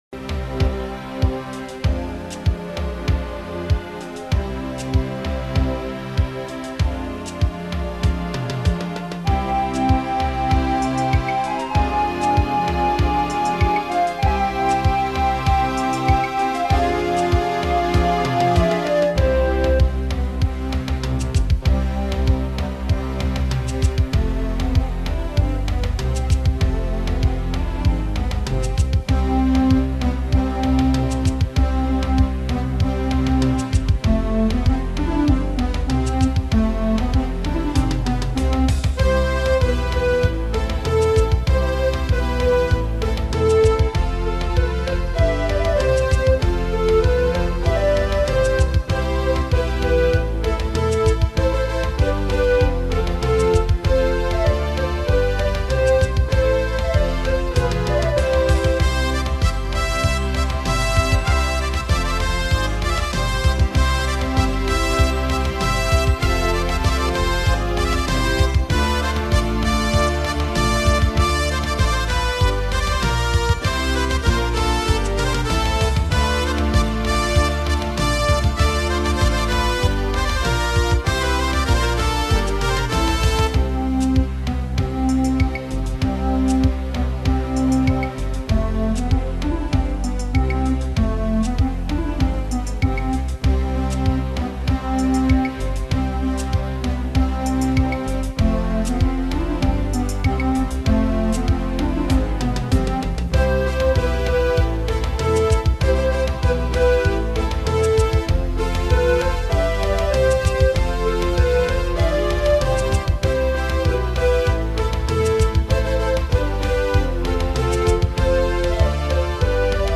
سرودهای انقلابی